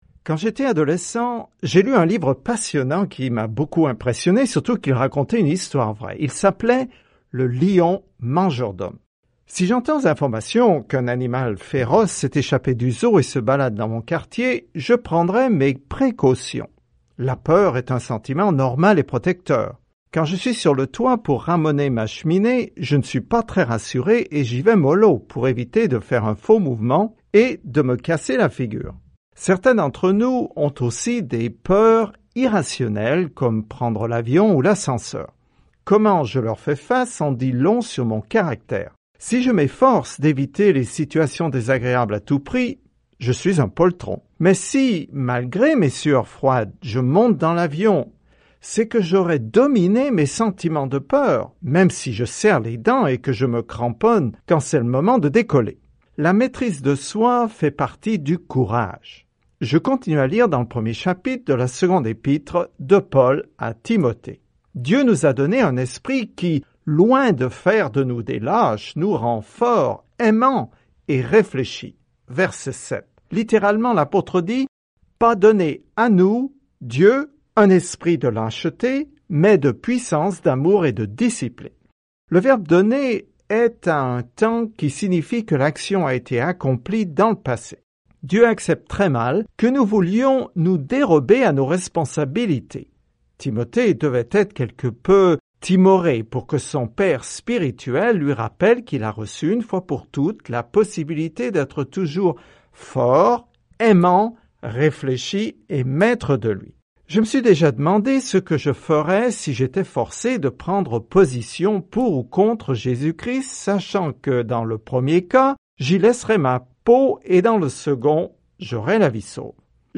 Écritures 2 Timothée 1:7-18 Jour 1 Commencer ce plan Jour 3 À propos de ce plan La deuxième lettre à Timothée appelle le peuple de Dieu à défendre la parole de Dieu, à la garder, à la prêcher et, si nécessaire, à souffrir pour elle. Parcourez quotidiennement 2 Timothée en écoutant l’étude audio et en lisant des versets sélectionnés de la parole de Dieu.